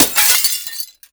GLASS_Window_Break_05_mono.wav